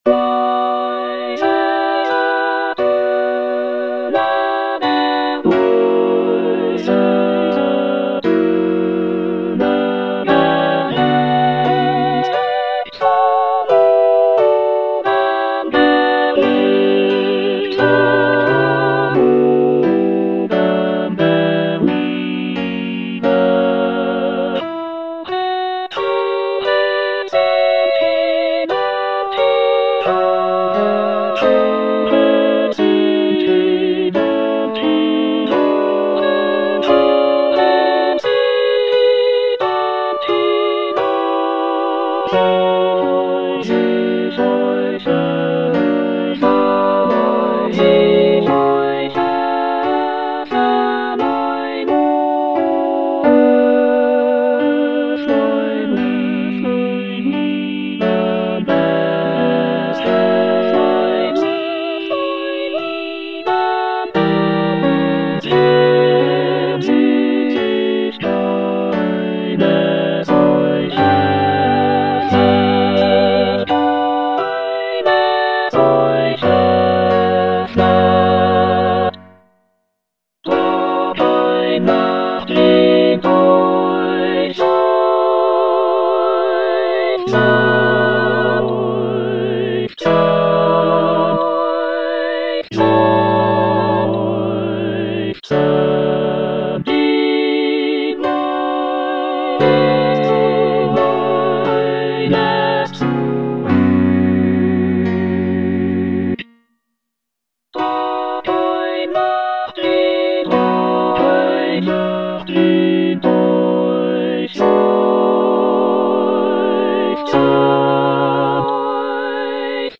"Nachtwache I op. 104 no. 1" is a composition by Johannes Brahms, a German Romantic composer. It is the first piece from his choral work titled "Vier Gesänge" (Four Songs), written in 1888 for mixed choir and orchestra. The piece showcases Brahms' mastery in blending rich harmonies and expressive melodies. "Nachtwache I" translates to "Night Watch I," and the music beautifully captures a serene and contemplative nocturnal atmosphere.